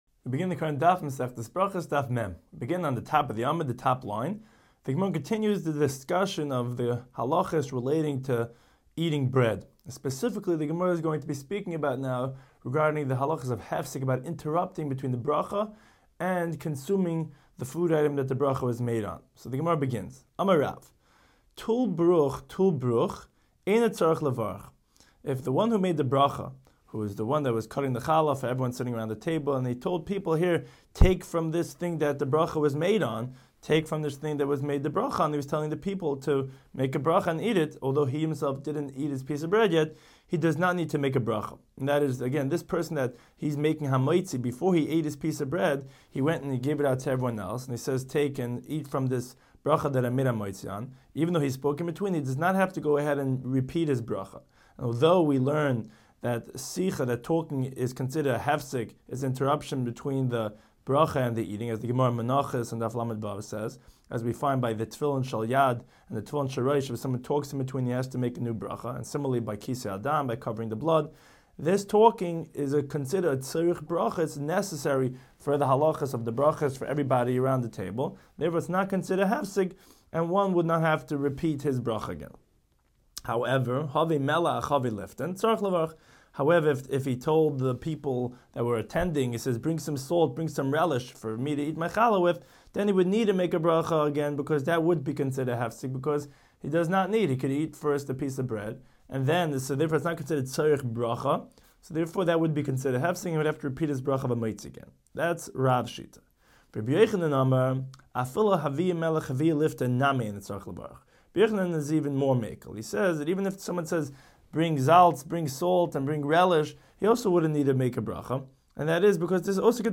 Daf Hachaim Shiur for Berachos 40